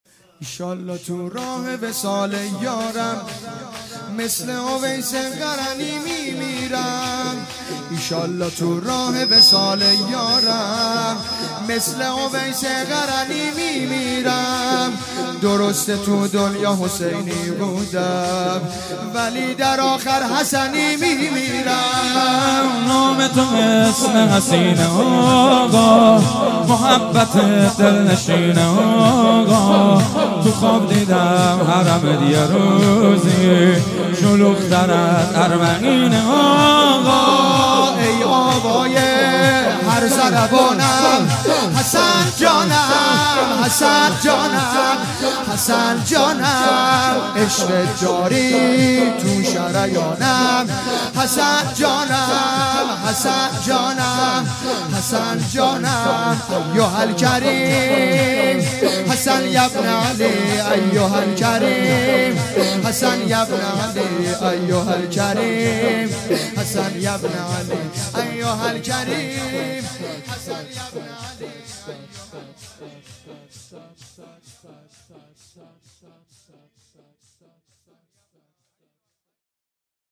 روضه انصارالزهرا سلام الله علیها
ان شاالله تو راه وصال یارم _ شور